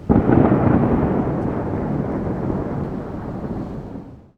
Thunder_2.ogg